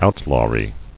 [out·law·ry]